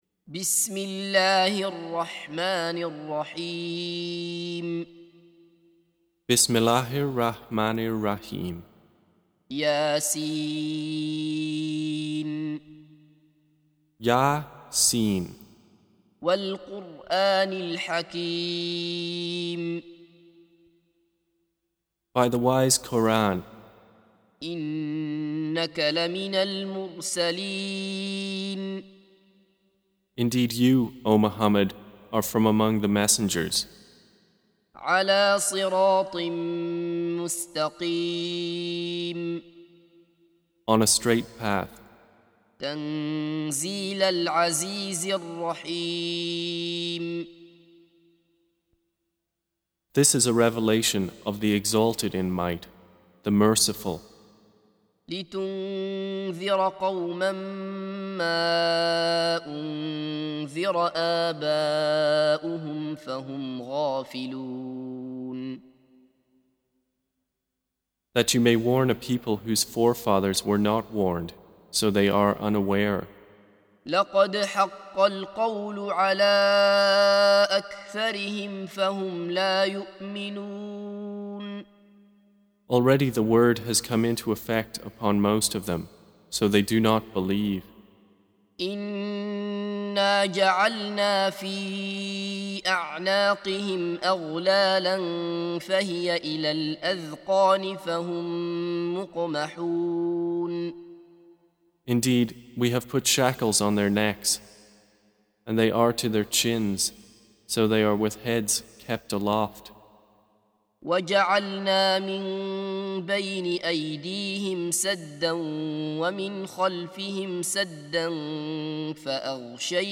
Surah Repeating تكرار السورة Download Surah حمّل السورة Reciting Mutarjamah Translation Audio for 36. Surah Y�S�n. سورة يس N.B *Surah Includes Al-Basmalah Reciters Sequents تتابع التلاوات Reciters Repeats تكرار التلاوات